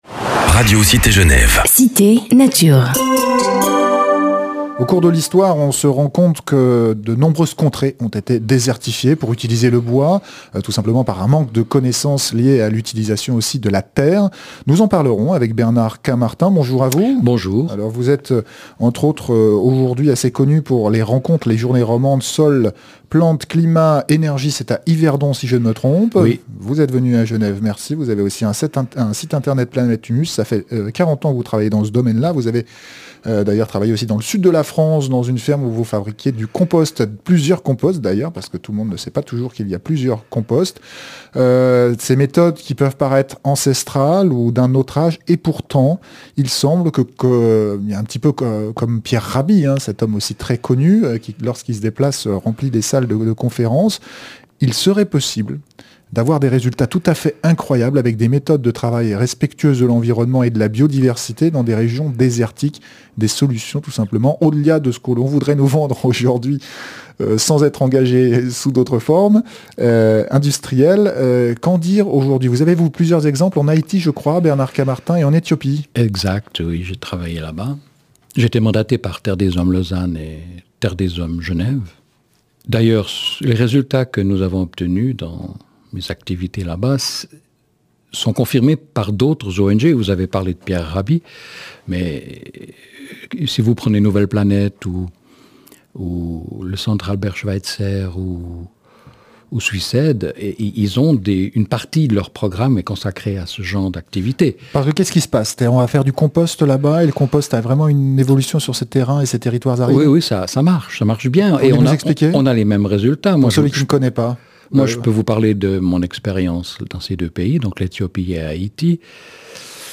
Entretien, résumé en quelques minutes d’une quarantaine d’années d’activités et d’expériences dans le domaine de la régénération des sols, la lutte contre l’érosion et la désertification, la prévention des famines et des sécheresses.